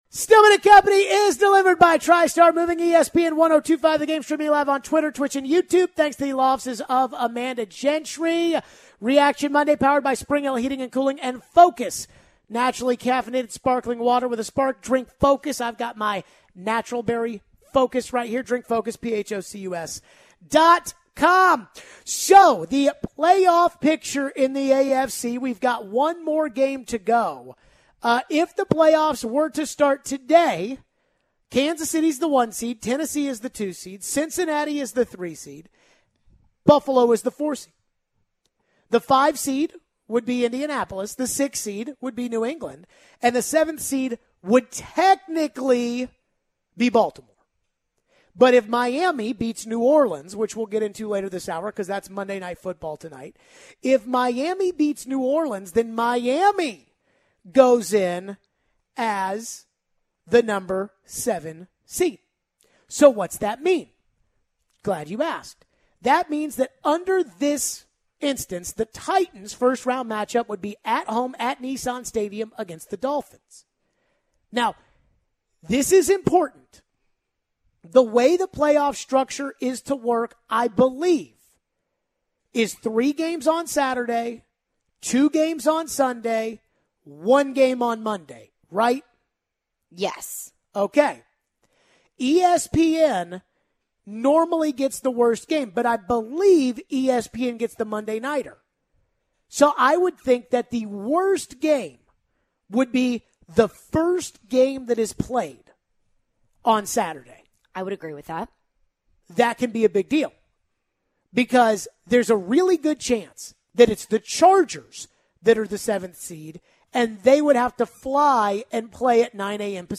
We take your phones. What do we want to see in the MNF game tonight?